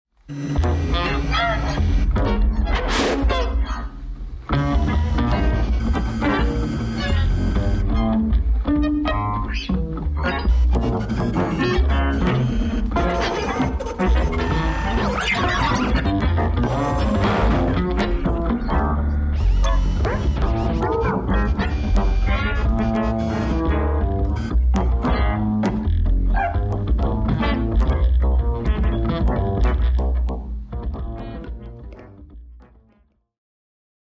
at morphine raum, berlin